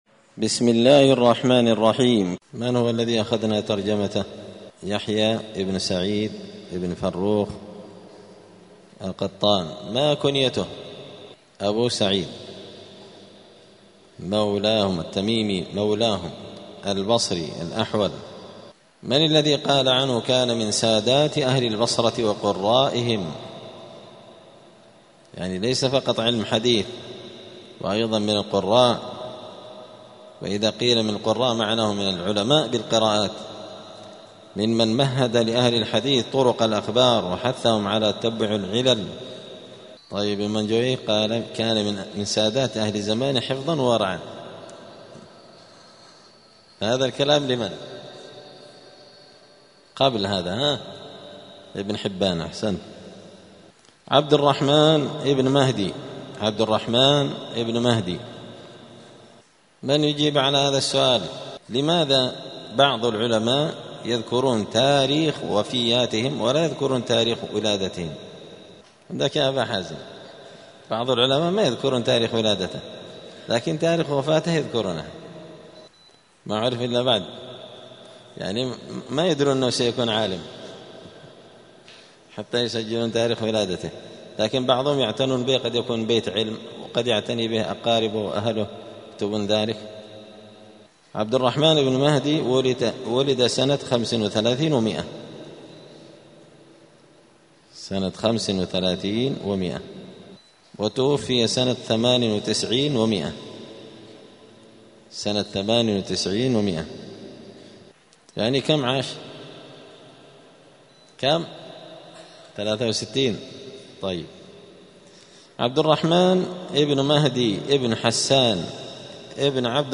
دار الحديث السلفية بمسجد الفرقان قشن المهرة اليمن 📌الدروس اليومية